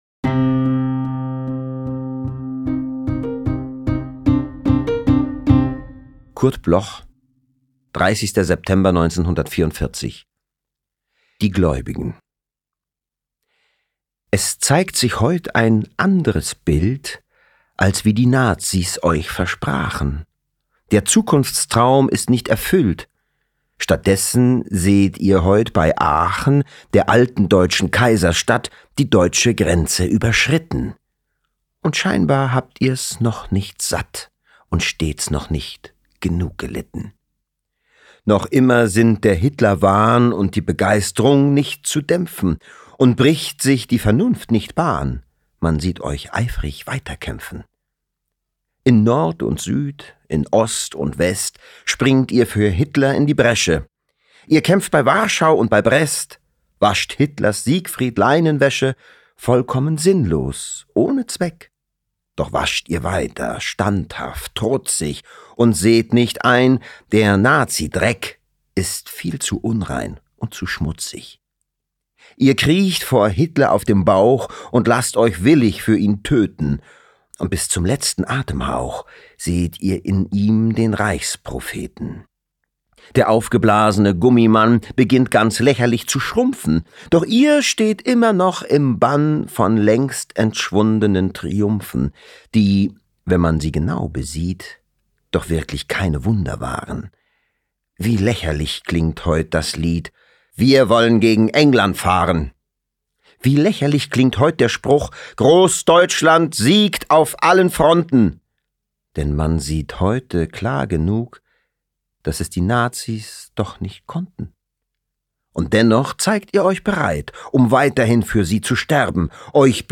Devid-Striesow-Die-Glaeubigen-mit-Musik_raw.mp3